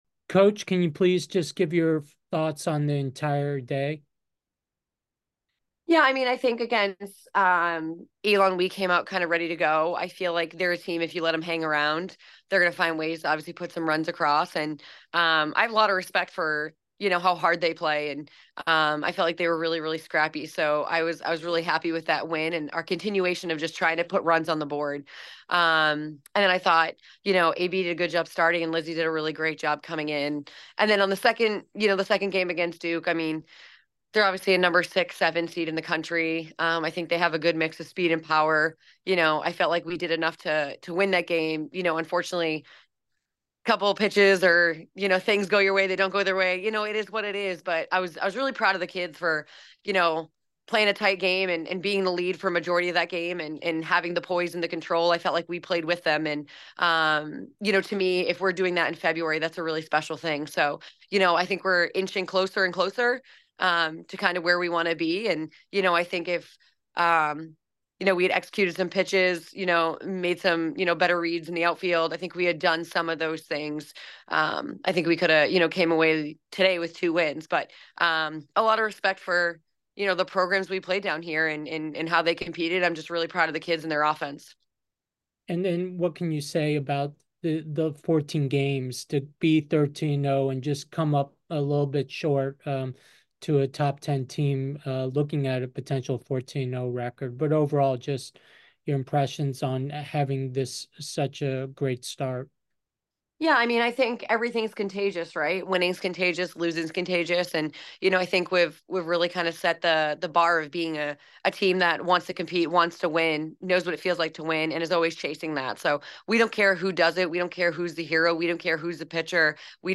Duke Invitational Day 2 Postgame Interview